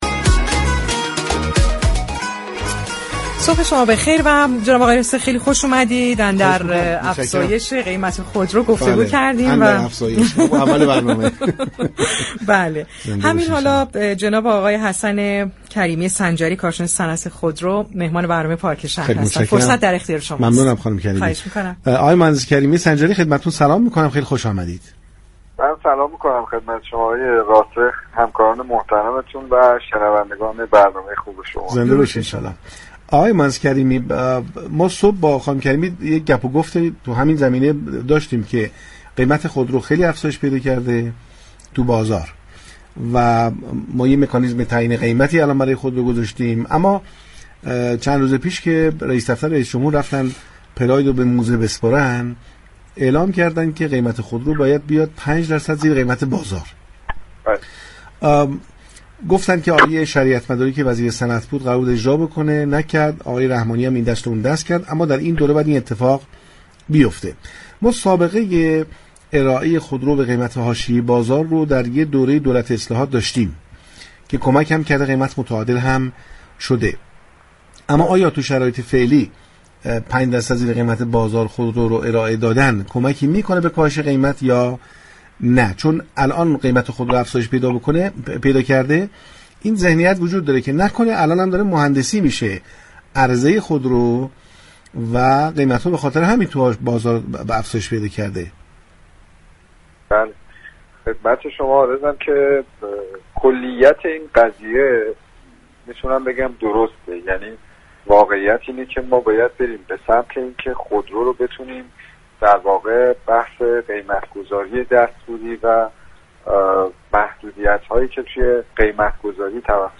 پارك شهر رادیو تهران گفتگو كردند.